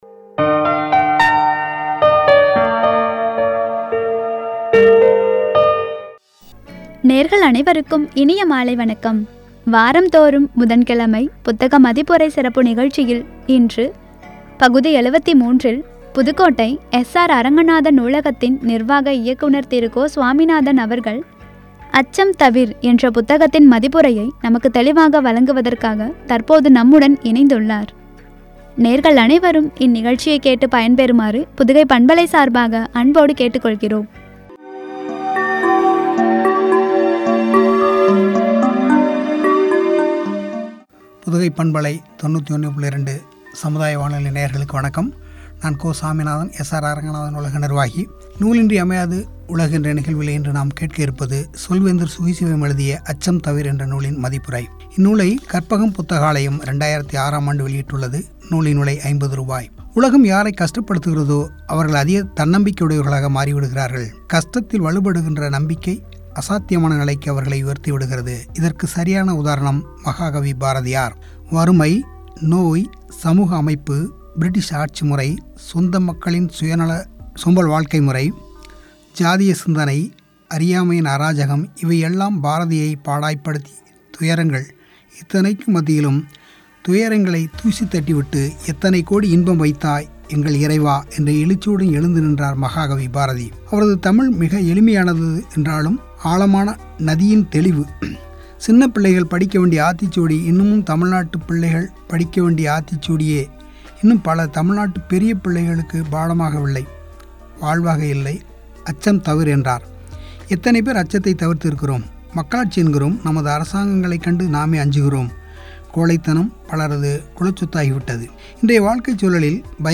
புத்தக மதிப்புரை